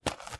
STEPS Dirt, Walk 04.wav